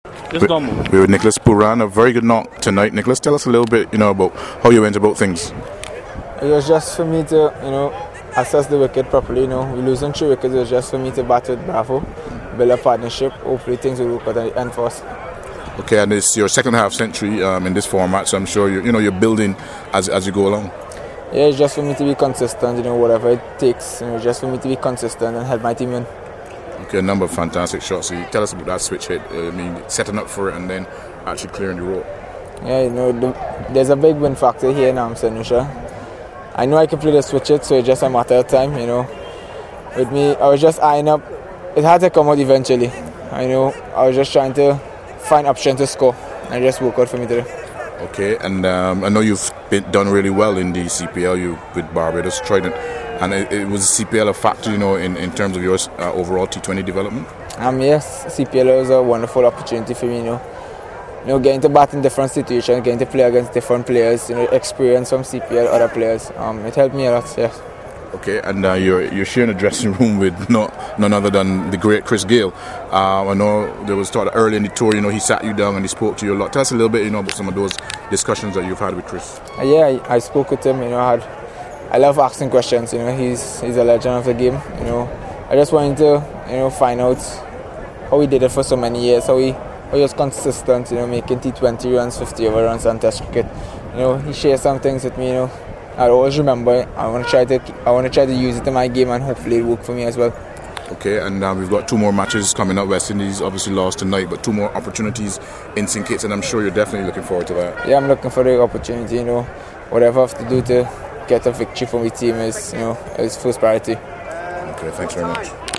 West Indies batsman Nicholas Pooran spoke to CWI Media after the first match in the Sandals Twenty20 International Series.